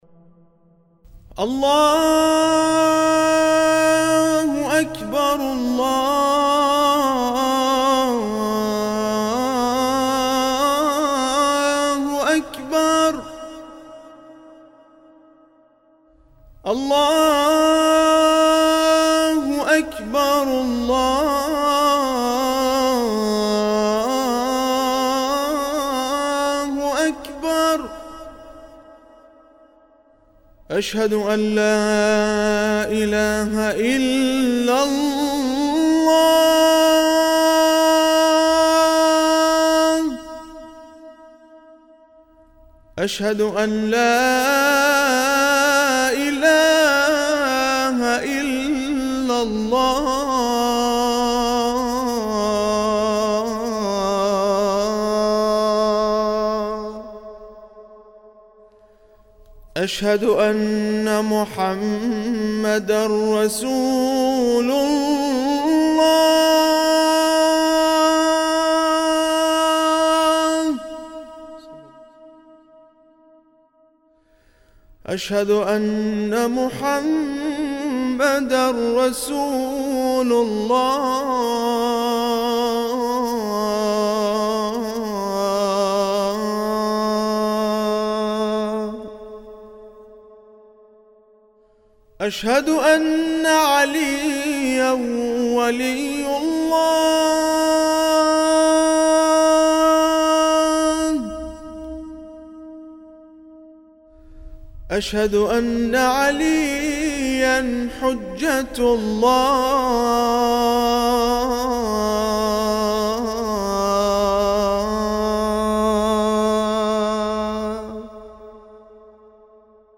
الأذان